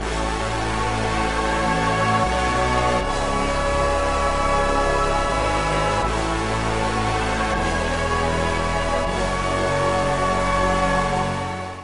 硬式嘻哈合成器
描述：艰辛
标签： 162 bpm Hip Hop Loops Synth Loops 1.99 MB wav Key : Unknown
声道立体声